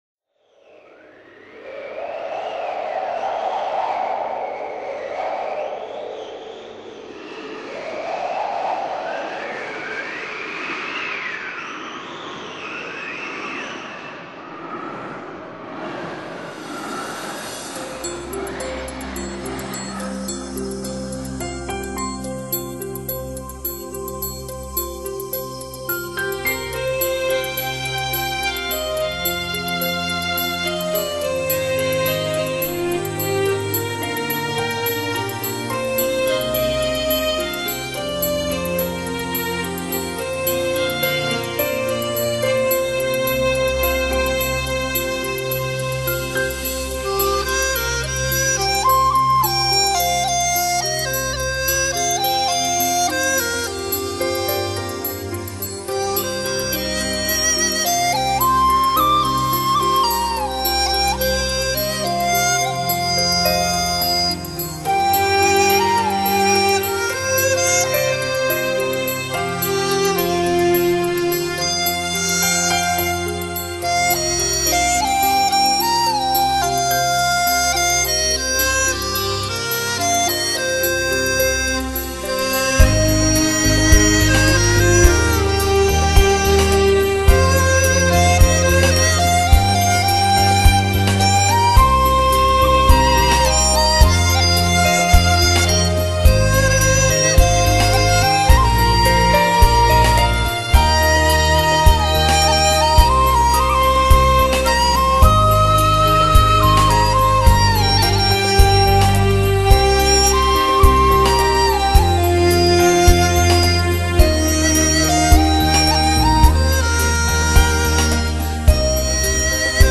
笛子演奏